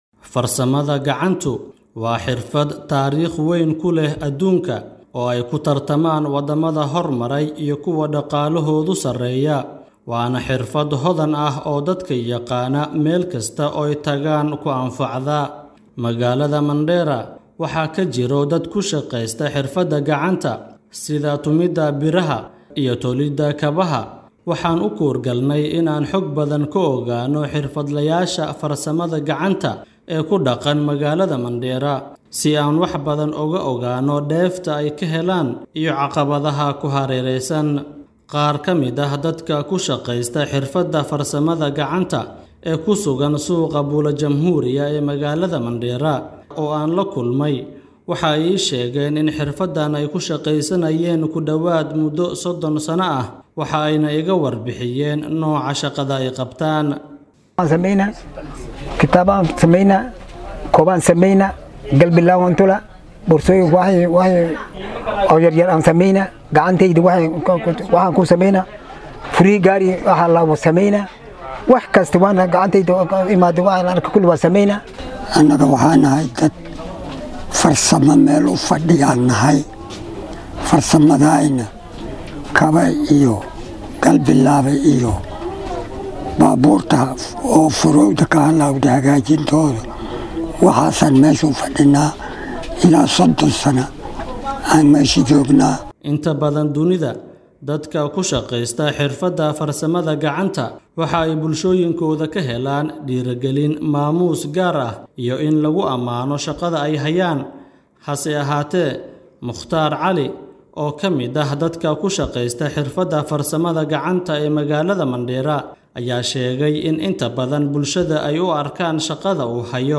DHAGEYSO:Odayaal muddo 30 sano ah ku shaqeysanayay farsamada gacanta oo u warramay Star FM